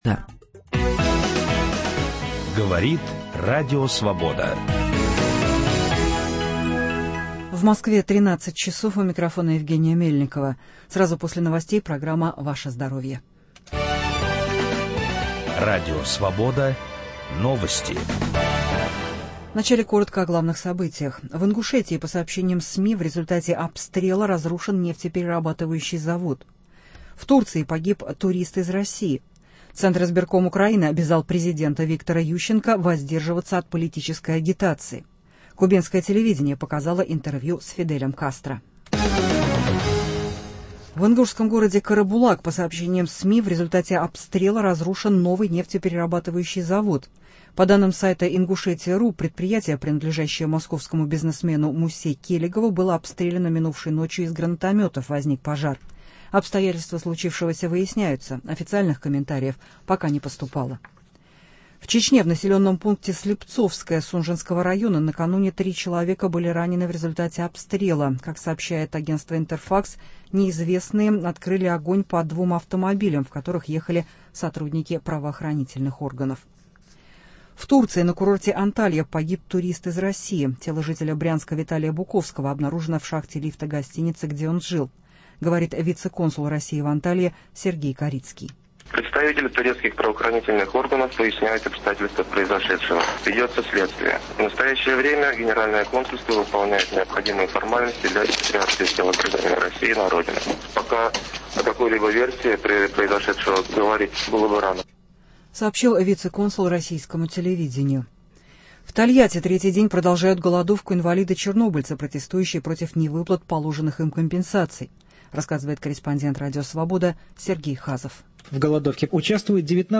Беседа с главным онкогематологом России, главным педиатром Москвы, академиком Александром Румянцевым. О раках крови, о будущем педиатрии, об оптимизме и не только